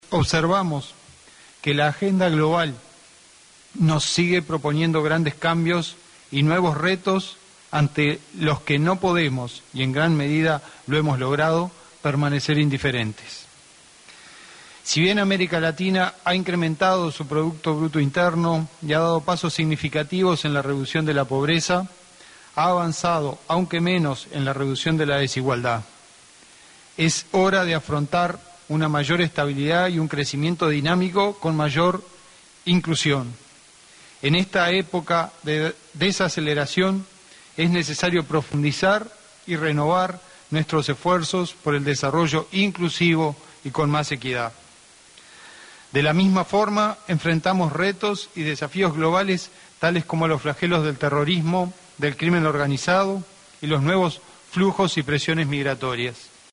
Durante su oratoria en la cumbre, realizada en República Dominicana, el vicepresidente Sendic advirtió de los grandes cambios y retos que impone la agenda de un mundo inmerso en la incertidumbre económica: